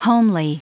Prononciation du mot homely en anglais (fichier audio)
Prononciation du mot : homely